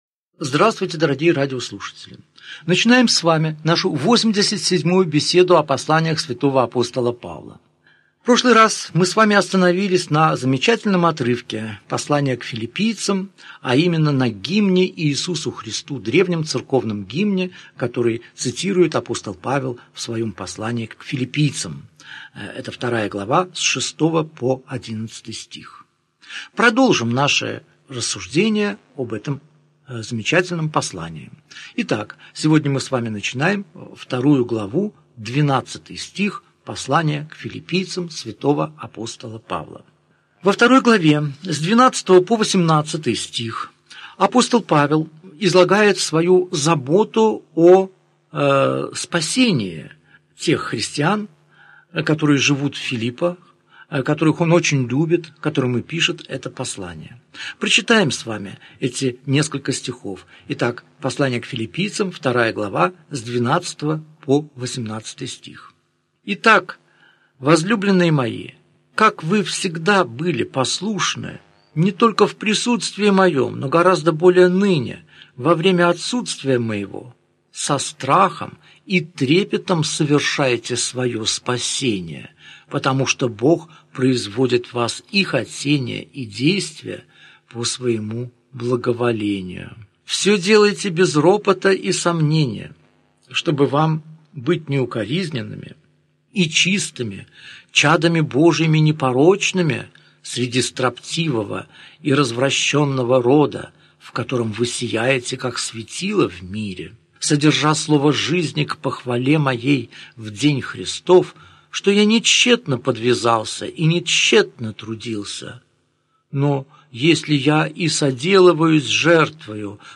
Аудиокнига Беседа 87. Послание к Филиппийцам. Глава 2 – глава 3, стих 11 | Библиотека аудиокниг